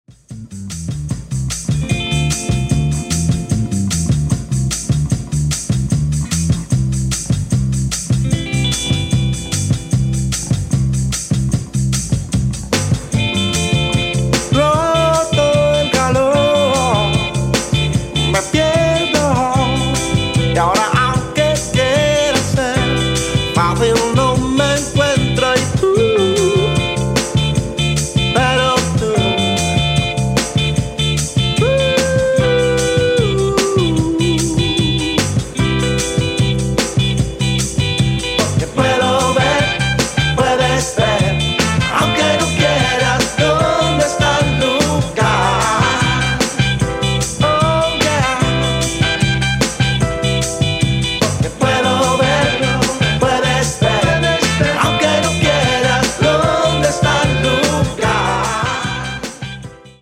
Splendid Spanish soul